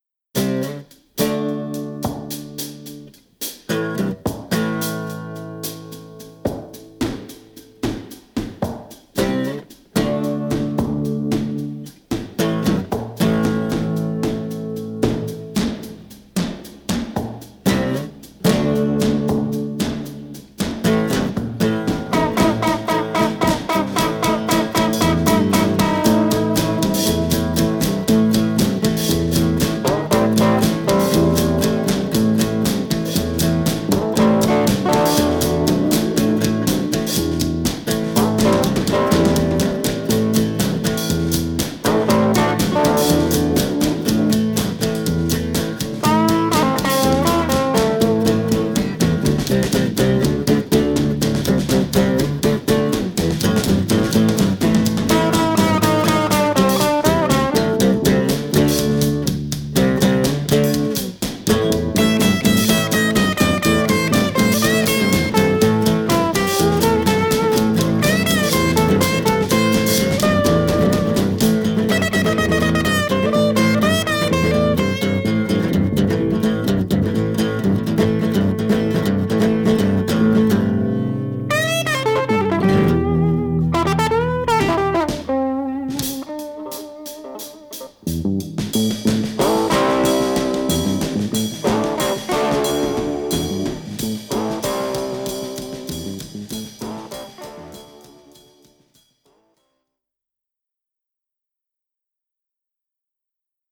wordless
a succinct jam under 2 minutes
its guitar caustic and biting, the drumming rapid